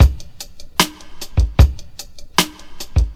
• 76 Bpm 2000s Hip-Hop Breakbeat Sample C Key.wav
Free drum loop sample - kick tuned to the C note. Loudest frequency: 1545Hz